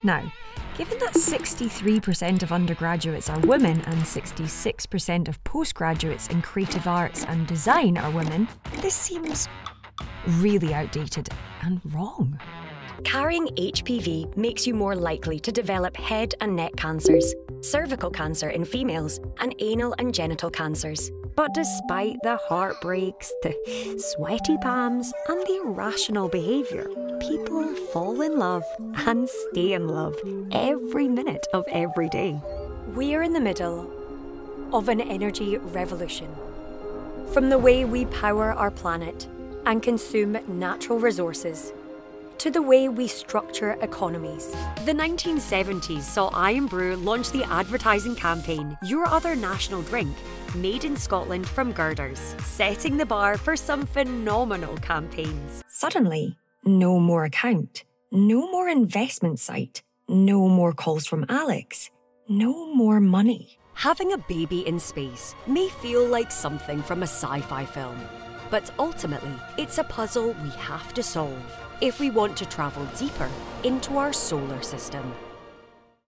Anglais (écossais)
Vidéos d'entreprise
I’m a Scottish full-time Voiceover Artist with a broadcast quality studio.
Fully sound insulated studio with acoustic treatment
Mic: Rode NT2-A
Contralto